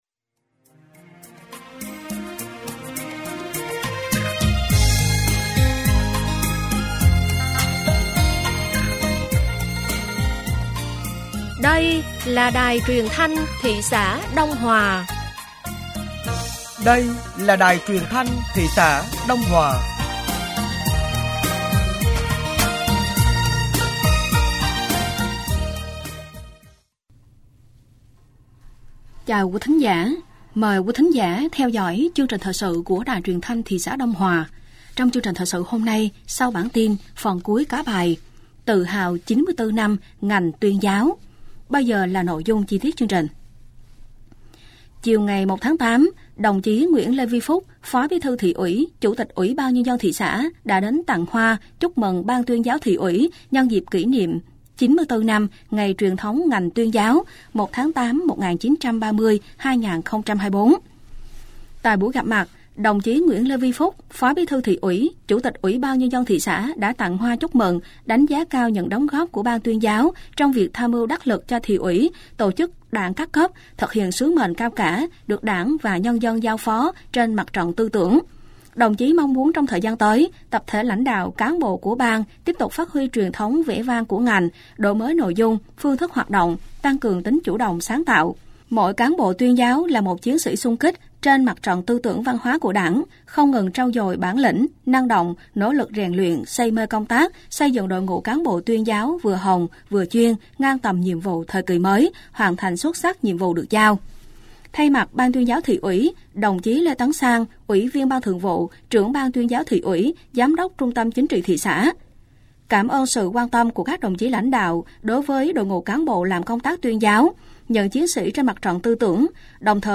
Thời sự tối ngày 01 và sáng ngày 02 tháng 8 năm 2024